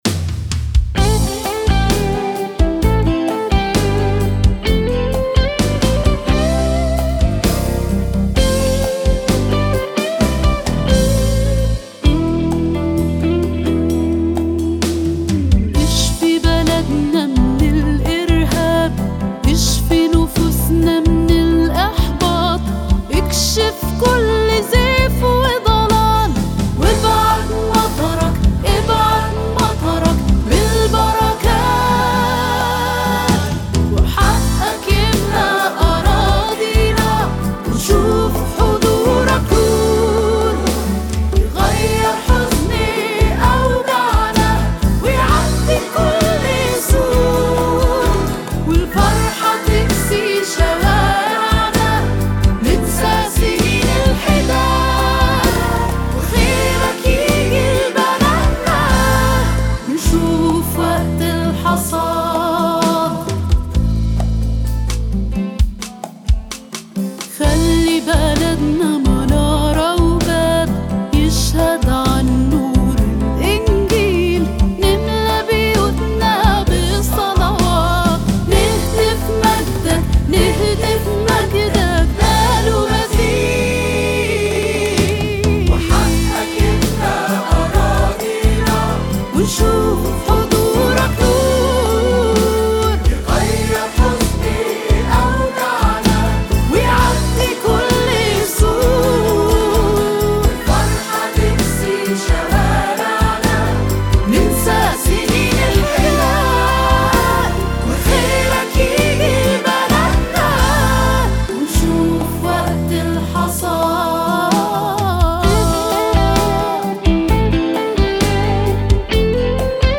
ترانيم